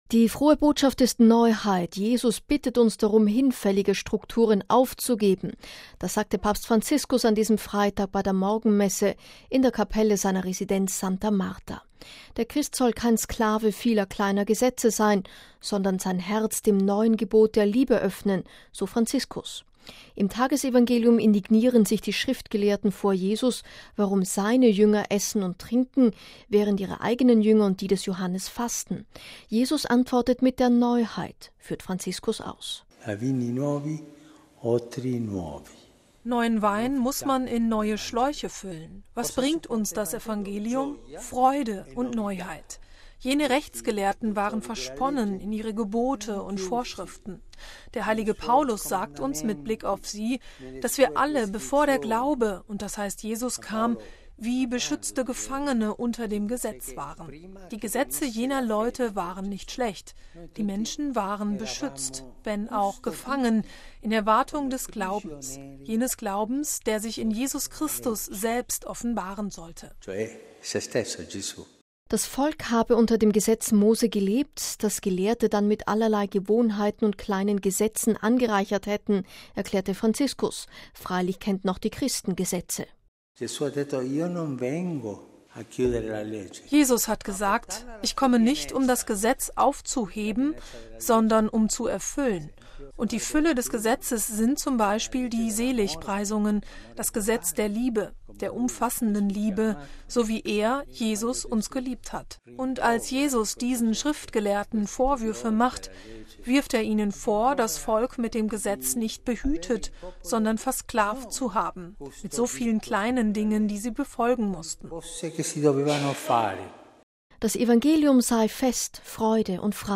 Papstpredigt: „Fürchtet euch nicht vor den Neuerungen in der Kirche“
Das sagte Papst Franziskus an diesem Freitag bei der Morgenmesse in der Kapelle seiner Residenz Santa Marta. Der Christ soll kein „Sklave vieler kleiner Gesetze“ sein, sondern sein Herz dem neuen Gebot der Liebe öffnen, so Franziskus.